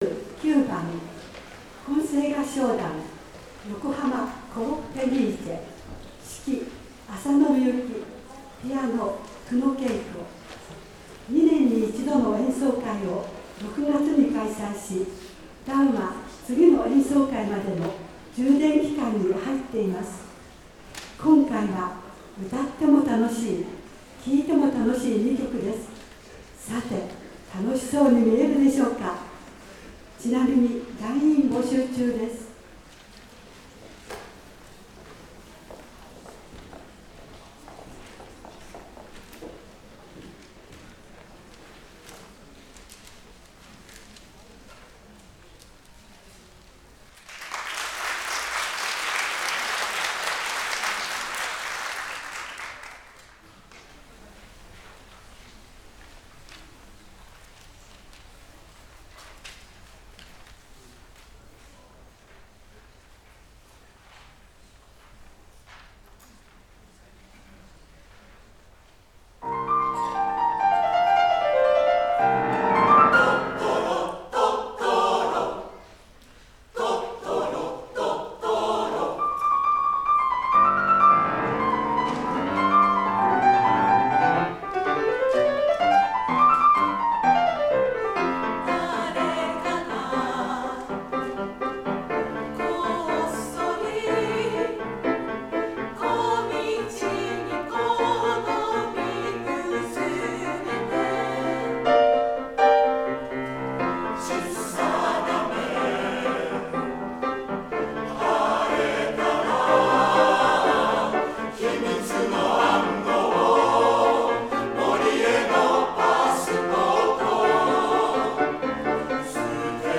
fenice_symbol 混声合唱団
○場所：横浜市開港記念会館／講堂
[実況録音(.mp3)]
安定した大人のコーラスと言う
混声でのトトロ、リズム感がすばらしく
パートバランスもハーモニーも調和がとれていて